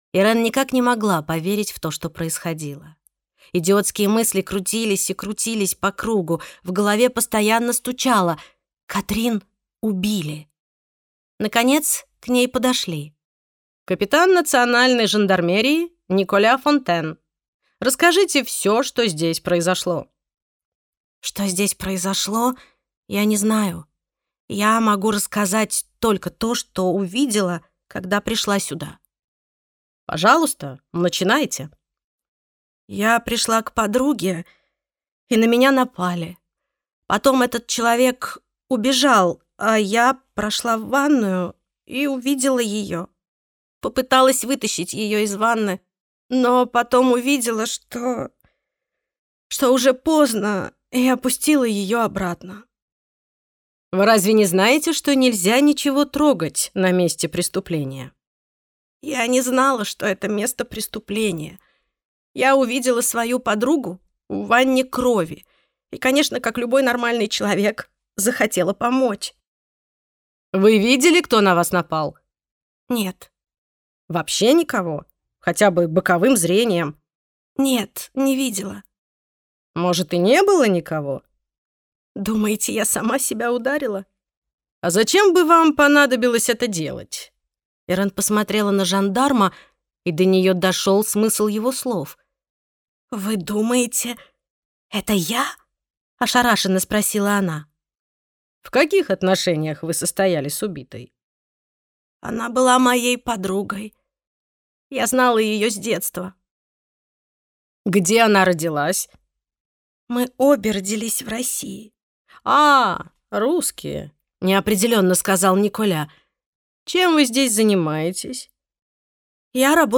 Аудиокнига Убийство в бухте ангелов | Библиотека аудиокниг